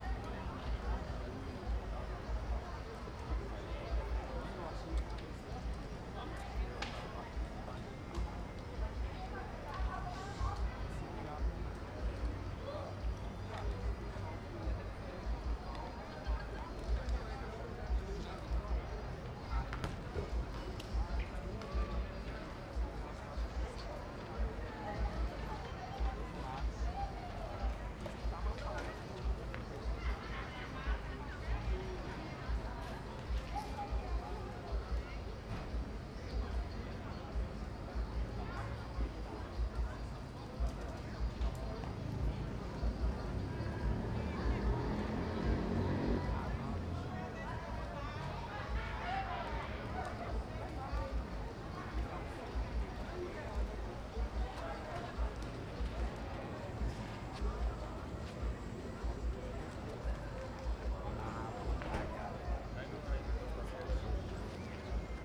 Quadra de futebol sao sebastiao com criancas, transito e apito no inicio Apito , Bola , Crianças , Futebol , Grade , Pessoas , Trânsito , Vozes masculinas
Stereo